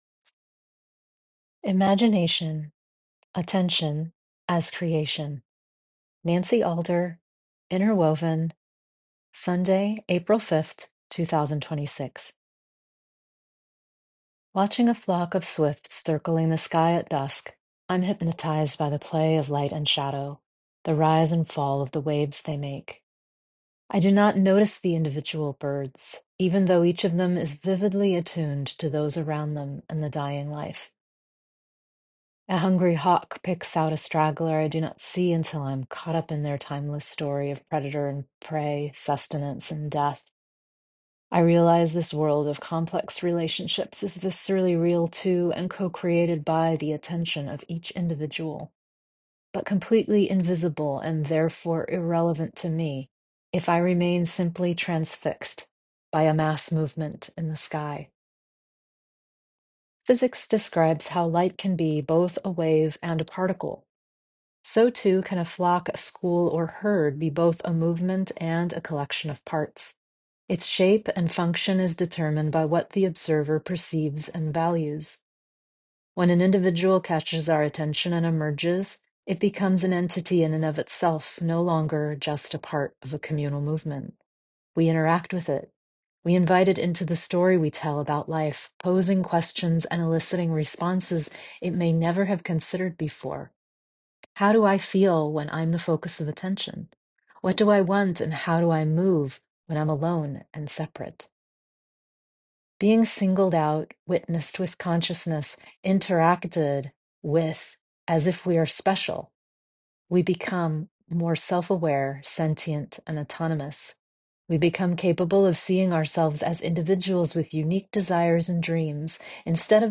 Enjoy this 10-minute read or let me read it to you here.